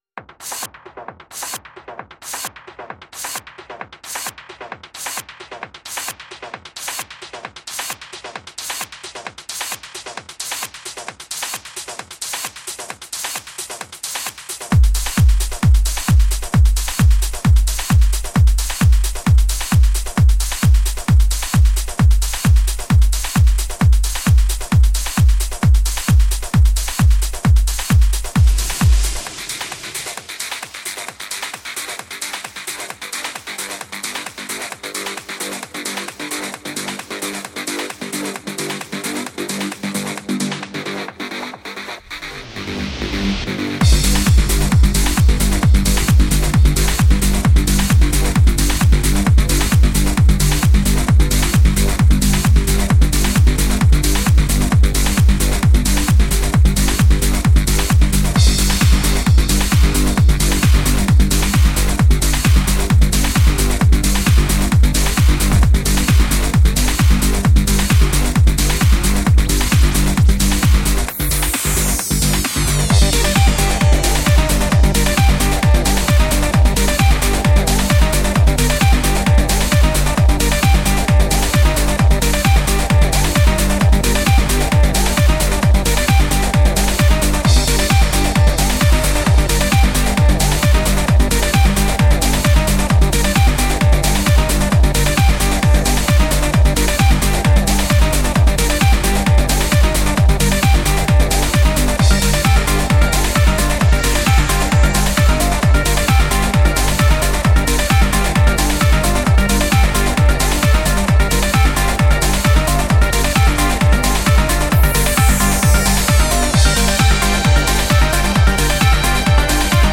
Жанр: Hard Trance